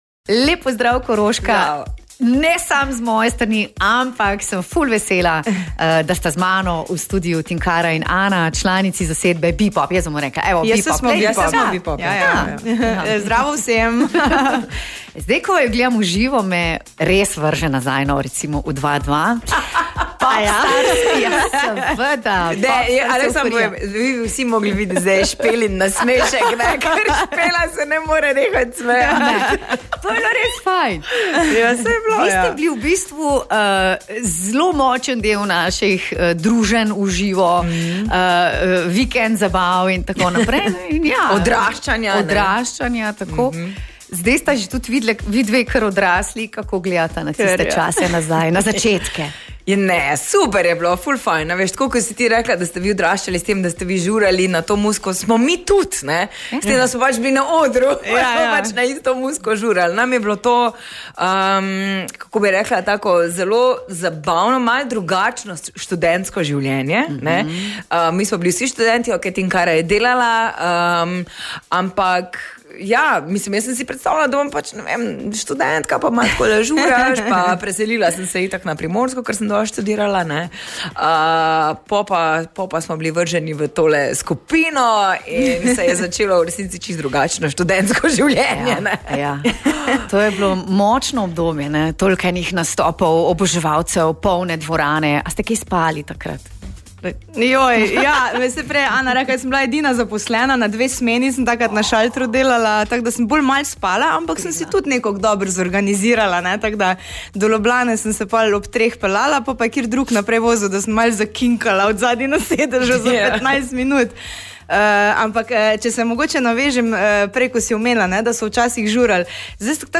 Ana Praznik in Tinkara Fortuna sta v studiu klepetali o začetkih skupine Bepop, ki je prva slovenska skupina, izbrana v licenčnem resničnostnem šovu Popstars.